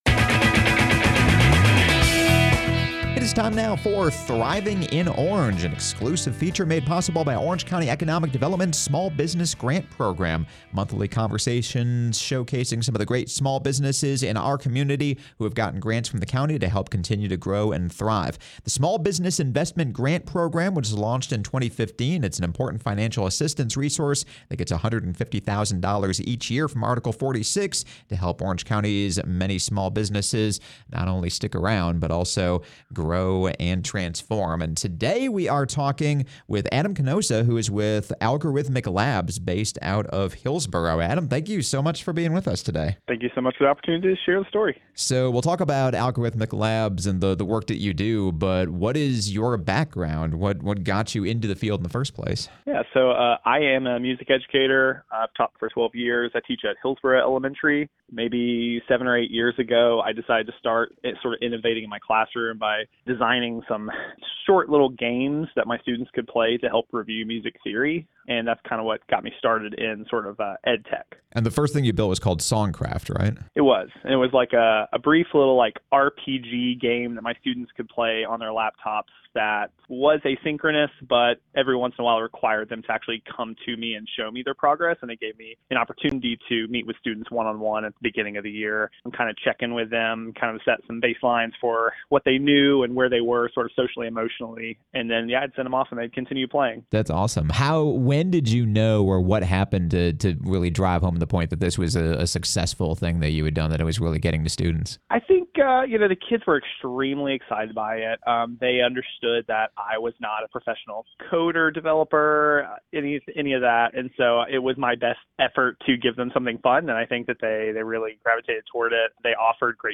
A monthly segment presented by Orange County Economic Development, “Thriving in Orange” features conversations with local business owners about what it’s like to live and work in Orange County, especially in light of the county’s small business grant program which launched in 2015 and has helped small businesses and small business owners with well over $100,000 in grants each year!